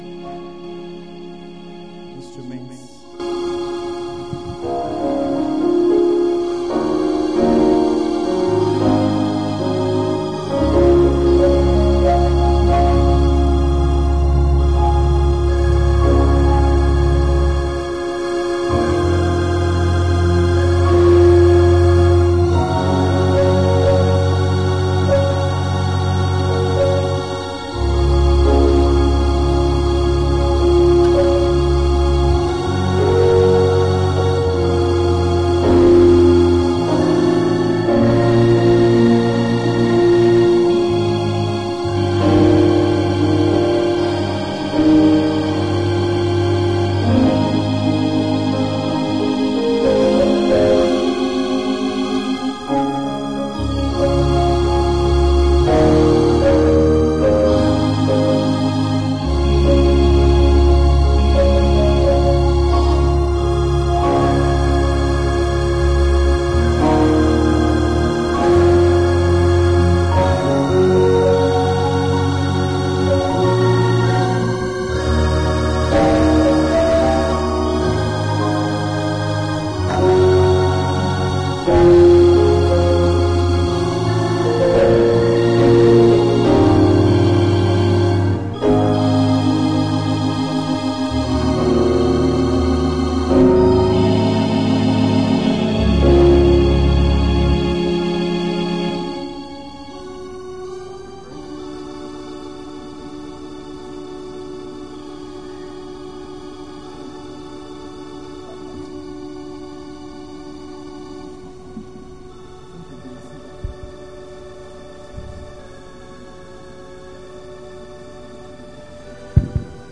Sunday Afternoon Service 19/01/25_